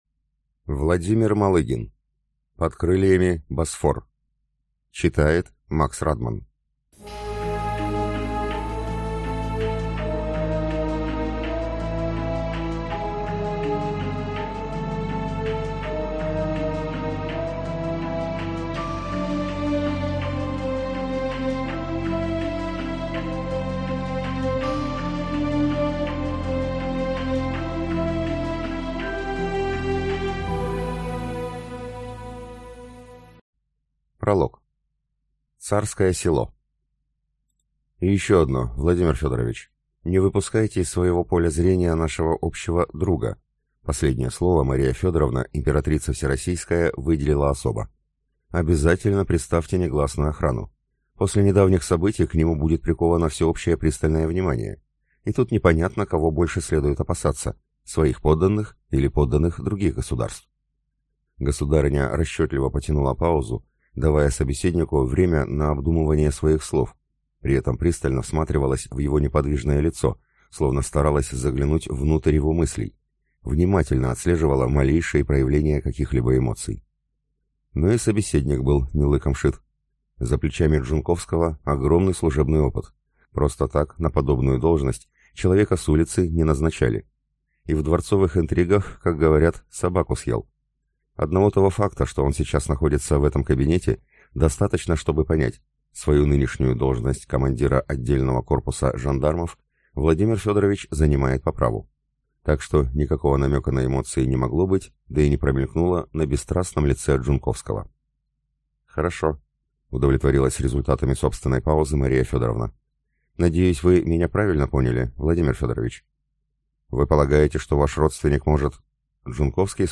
Аудиокнига Под крыльями Босфор | Библиотека аудиокниг